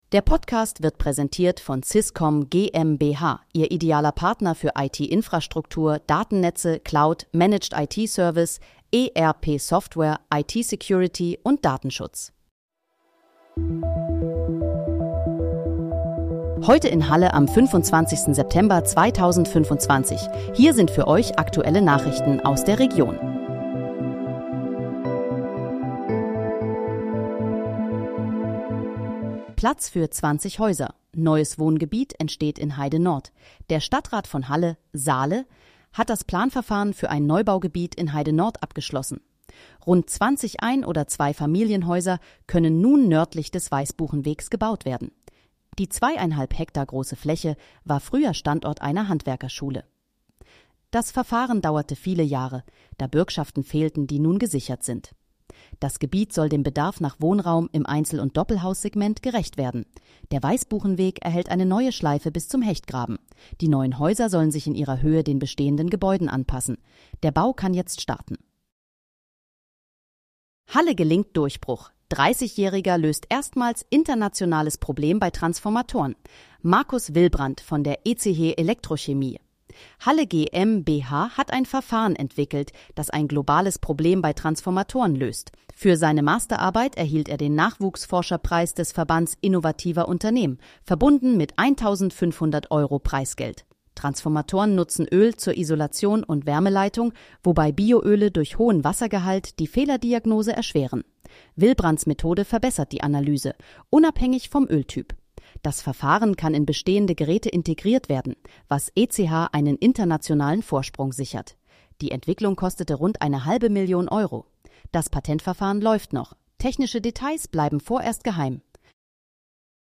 Heute in, Halle: Aktuelle Nachrichten vom 25.09.2025, erstellt mit KI-Unterstützung
Nachrichten